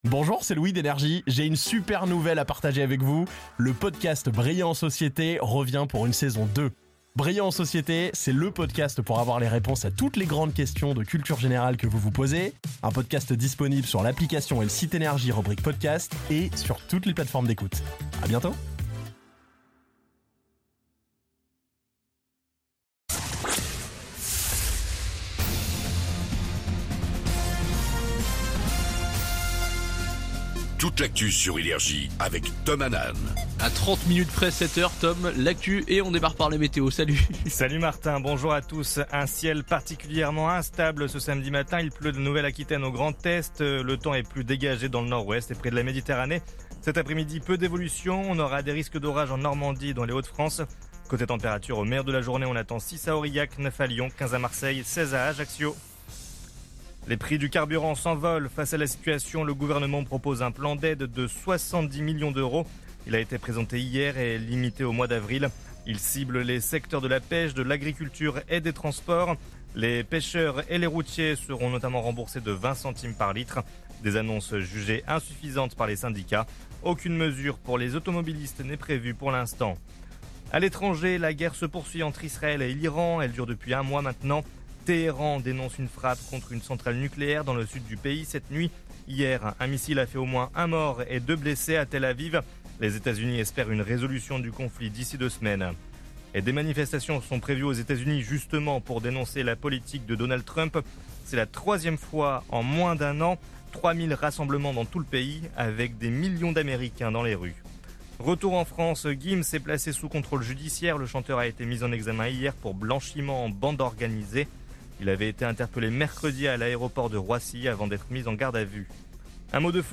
Réécoutez vos INFOS, METEO et TRAFIC de NRJ du samedi 28 mars 2026 à 07h00